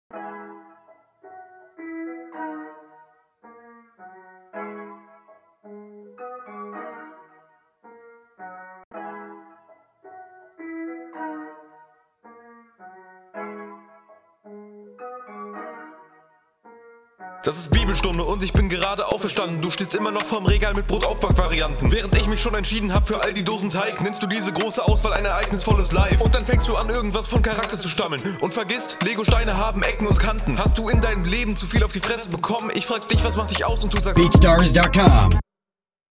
Flow: Sehr gut und ich liebe die Variation trotz der kurzen Zeit Text: alles kontert …